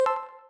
get-chip.ogg